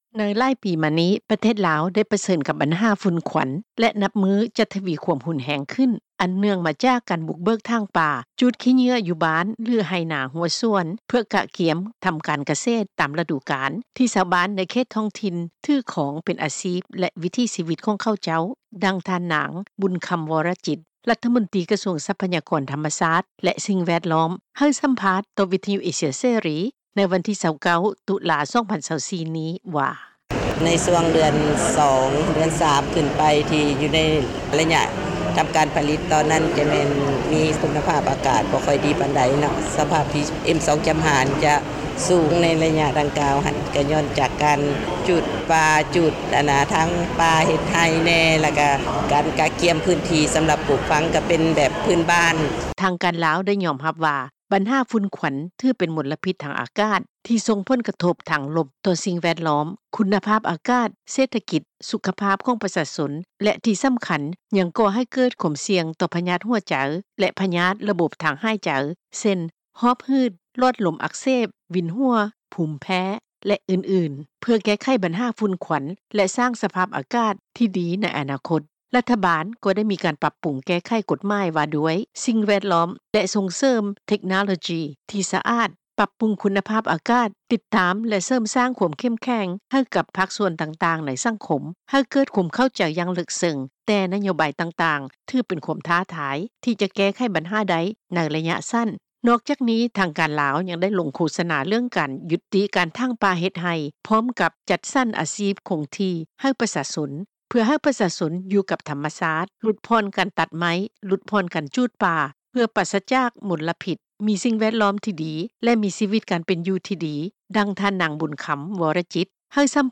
ດັ່ງ ທ່ານນາງ ບຸນຄຳ ວໍລະຈິດ ລັດຖະມົນຕີ ກະຊວງຊັບພະຍາກອນທຳມະຊາດ ແລະ ສິ່ງແວດລ້ອມ ໃຫ້ສຳພາດ ຕໍ່ວິທຍຸເອເຊັຽເສຣີ ໃນວັນທີ 29 ຕຸລາ 2024 ນີ້ວ່າ: